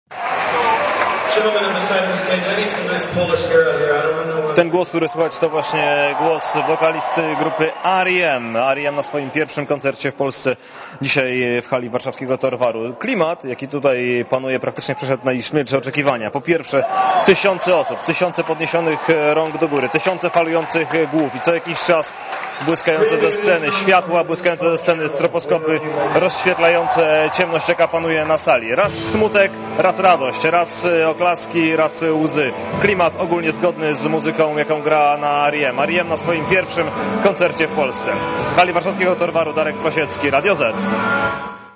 O tym mówi nasz reporter, który był na koncercie.